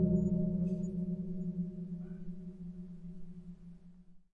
电风扇金属烤架样品 " 电风扇烤架空气中的金属撞击声
描述：电风扇作为打击乐器。击打和刮擦电风扇的金属格栅可以发出美妙的声音。
Tag: 金属的 混响 电动风扇 样品